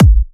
VFH3 132BPM Elemental Kick.wav